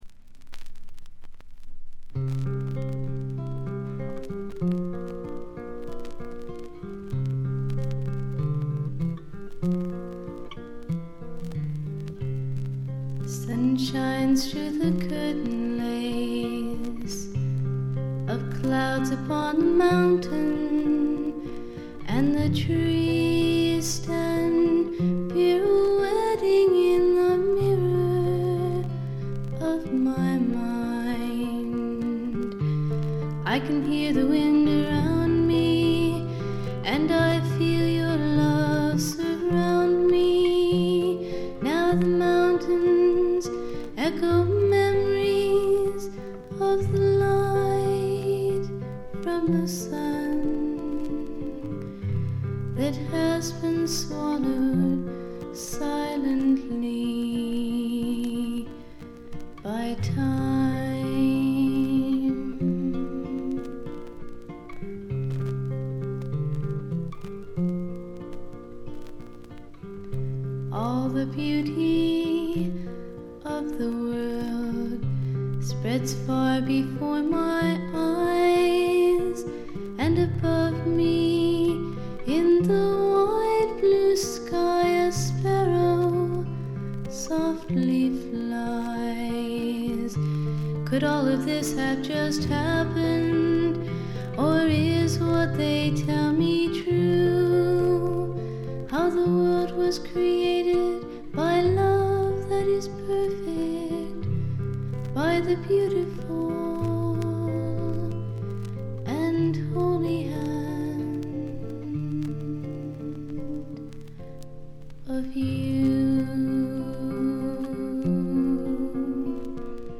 ところどころで軽微なチリプチ。気になるようなノイズはありません。
演奏はほとんどがギターの弾き語りです。
この純真なドリーミー感覚はめったに得られない貴重なものです。
試聴曲は現品からの取り込み音源です。